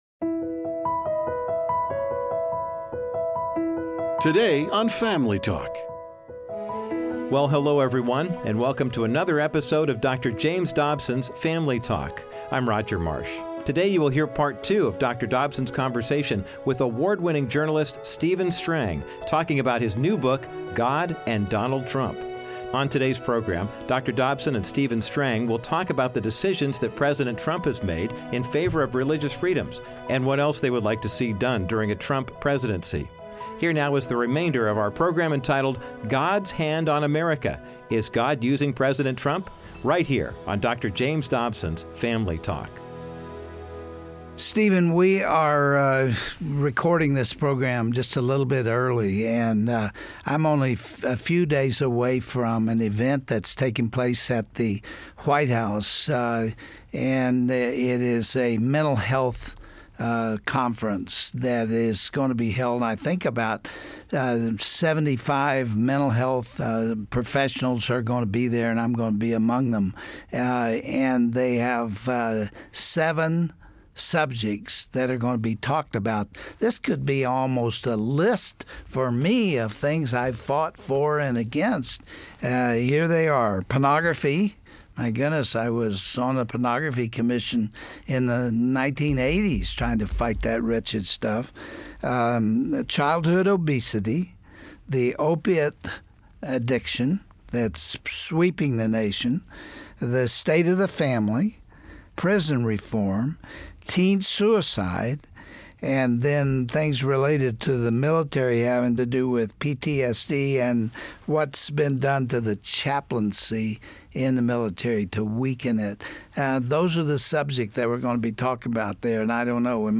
The two will discuss what President Trump has done for religious liberties so far and what they hope to see in the next 3 years. Dont miss this interview, today on Dr. James Dobsons Family Talk.